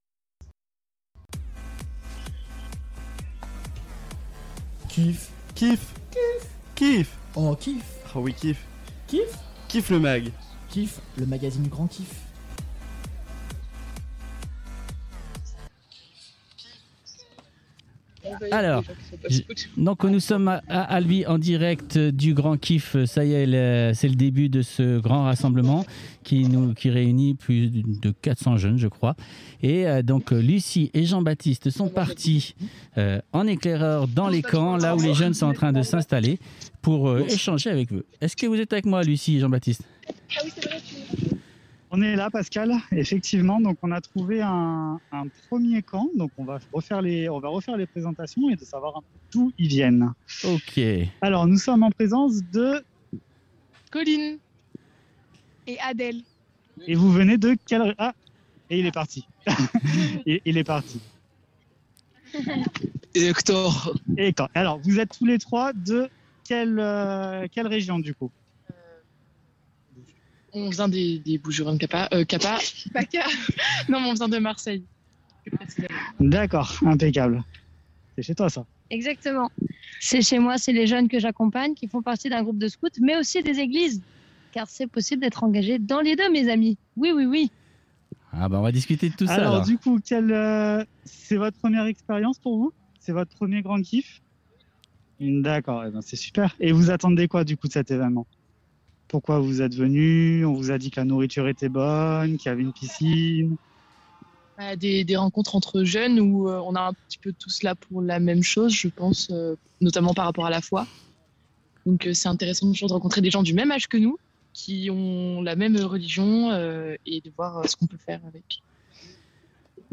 Numéro 1 du KIFFMAG le magazine du GRANDKIFF en direct de Albi